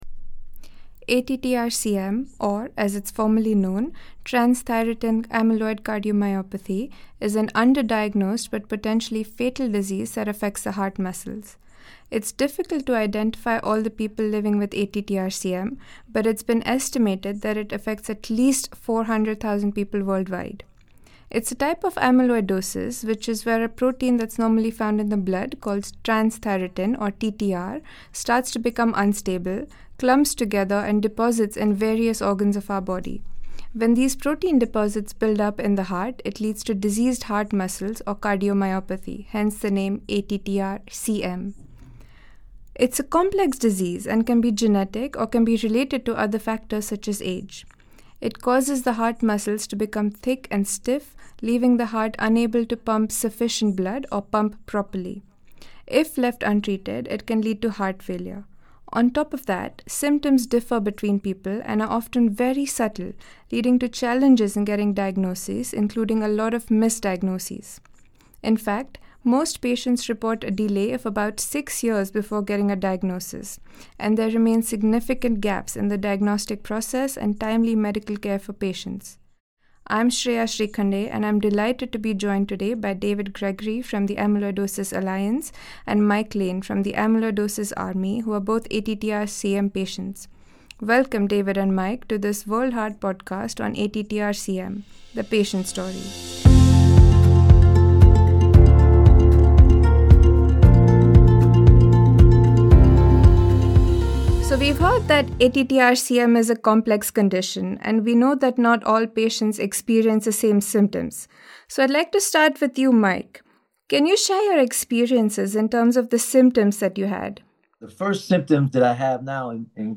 Two patient advocates share their journey with ATTR-CM, from missed symptoms and delayed diagnosis to treatment, advocacy, and hope for future patients.